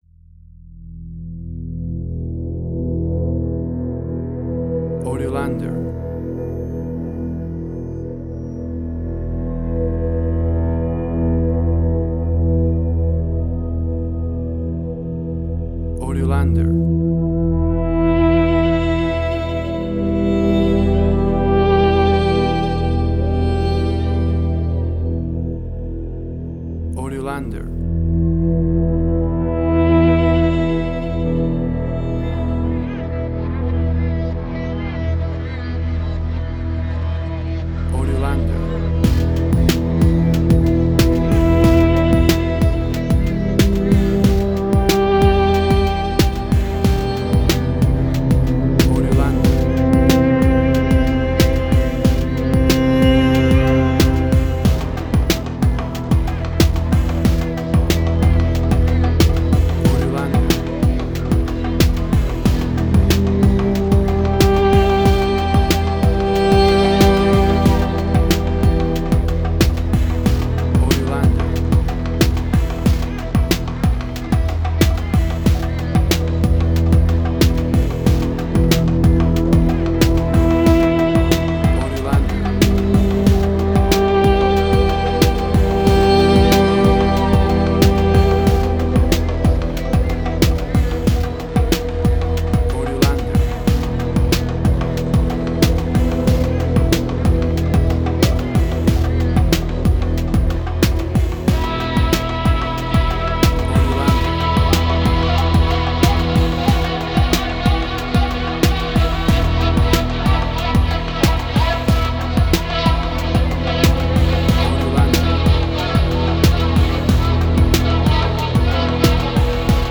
Middle Eastern Fusion.
Tempo (BPM): 92